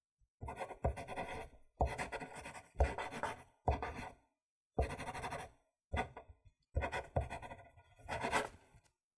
• writing with a pen.wav
Writing and scribling with a pen on a wooden desk, recorded with Tascam DR 40.
writing_with_a_pen_Uvd.wav